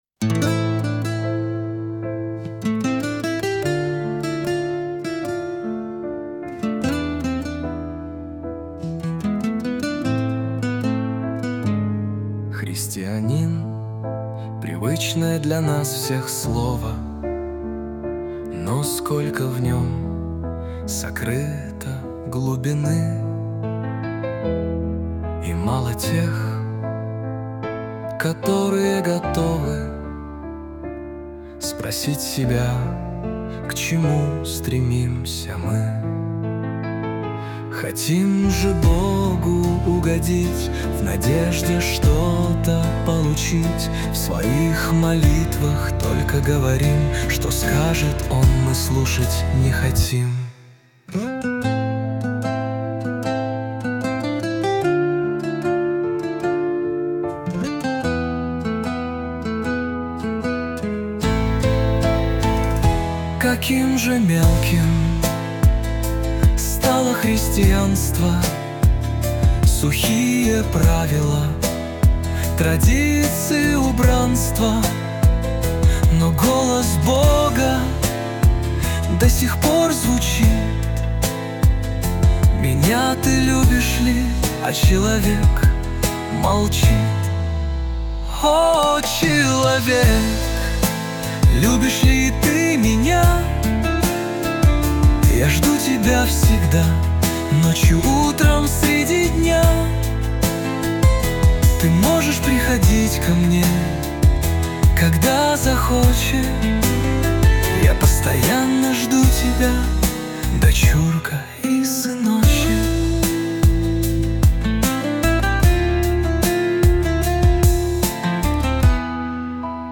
песня ai
221 просмотр 1035 прослушиваний 87 скачиваний BPM: 76